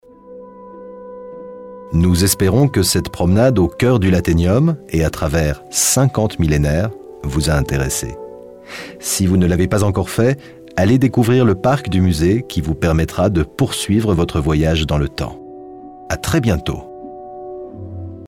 Fichier audio utilisé dans le parcours Audioguide FRA 3-4